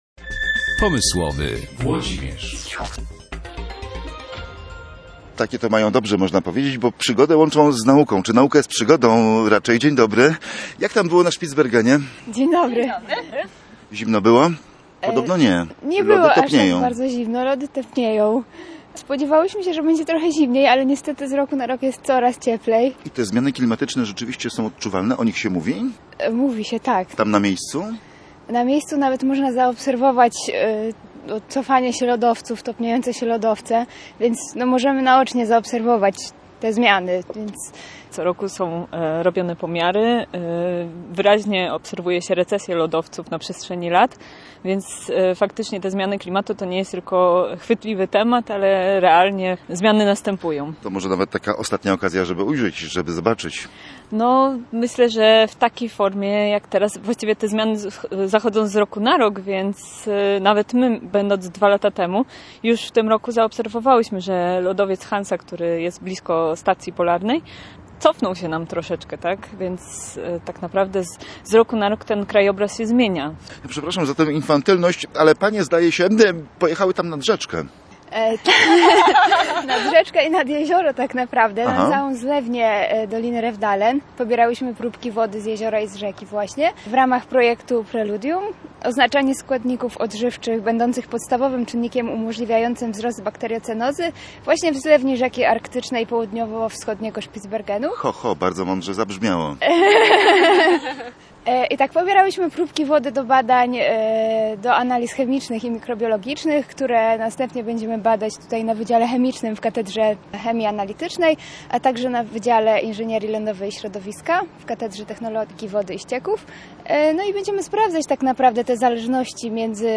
Rozmowa z badaczkami z Politechniki Gdańskiej